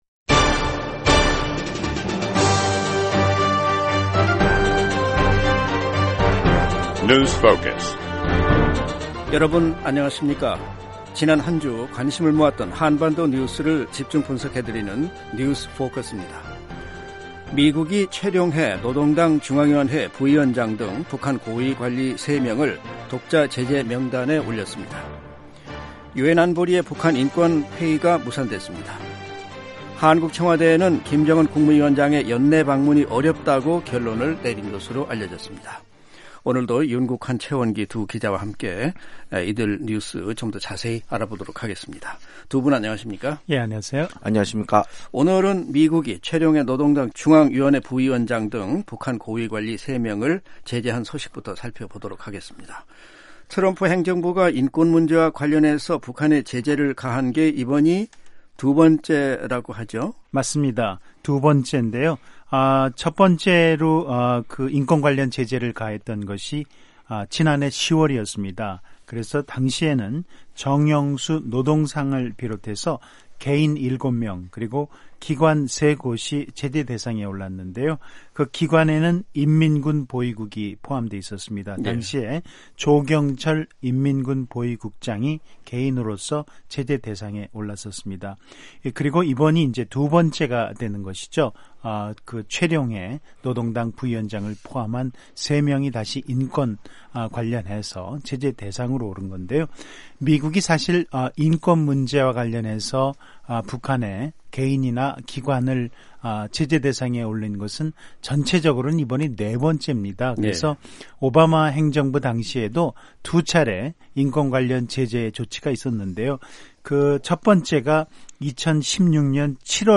지난 한 주 관심을 모았던 한반도 뉴스를 집중 분석해 드리는 뉴스 포커스입니다. 미국이 최룡해 노동당 중앙위원회 부위원장 등 북한 고위 관리 3명을 독자 제재 명단에 올렸습니다. 유엔 안보리의 북한 인권 회의가 무산됐습니다. 한국 청와대는 김정은 국무위원장의 연내 방문이 어렵다고 결론을 내린 것으로 알려졌습니다.